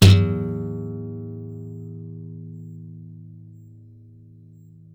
Sexta cuerda de una guitarra
cordófono
guitarra